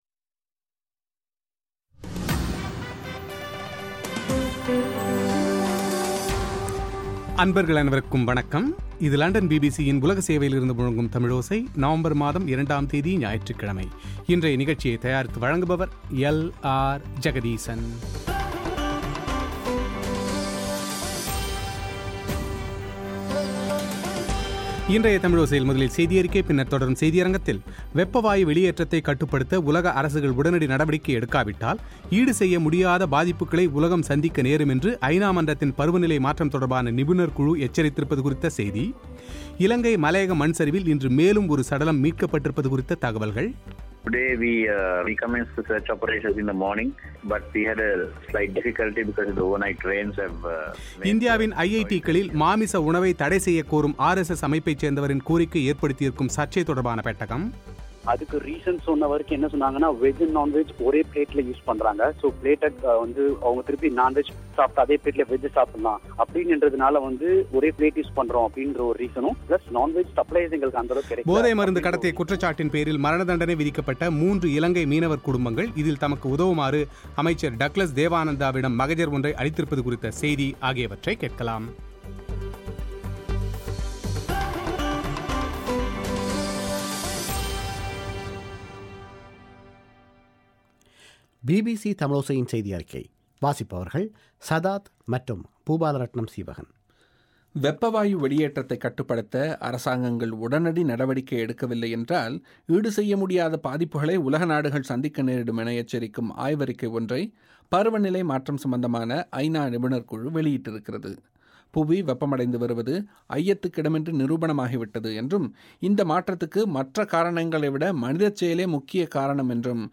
இலங்கை மலையக மண்சரிவில் இன்று மேலும் ஒரு சடலம் மீட்கப்பட்டிருப்பது குறித்த தகவல்கள்; பாதிக்கப்பட்ட பகுதிக்கு தமிழ்த்தேசிய கூட்டமைப்பின் குழு ஒன்று இன்று சென்று பாதிக்கப்பட்டவர்களை நேரில் சந்தித்திருப்பது பற்றி அந்த குழுவில் இருந்த தமிழ்த்தேசிய கூட்டமைப்பின் நாடாளுமன்ற உறுப்பினர் சுரேஷ் பிரேமச்சந்திரனின் செவ்வி;